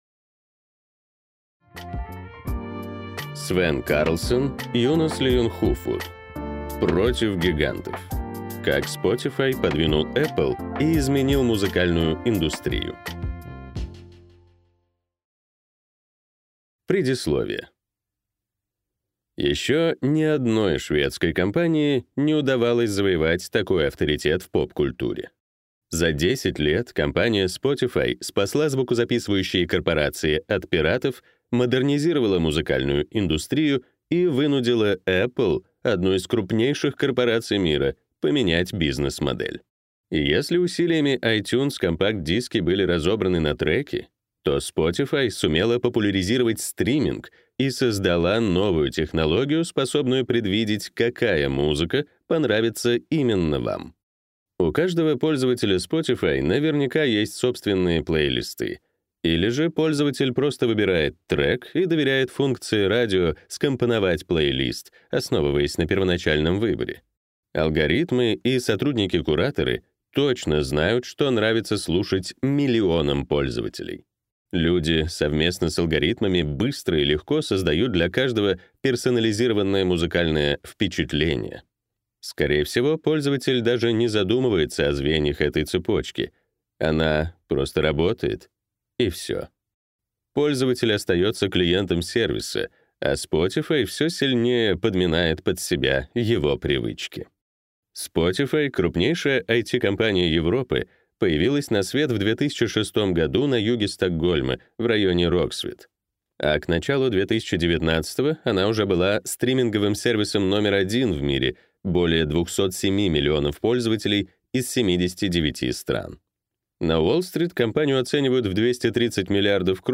Аудиокнига Против гигантов. Как Spotify подвинул Apple и изменил музыкальную индустрию | Библиотека аудиокниг